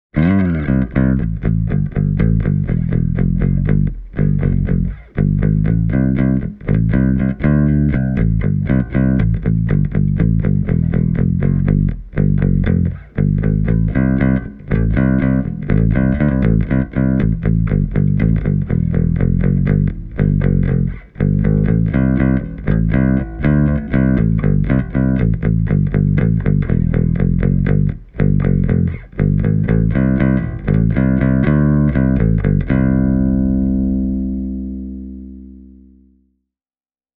• Audio interface used: Universal Audio Volt 2
Tässä esimerkissä soitan Rickenbacker 4003 -bassoani plektralla: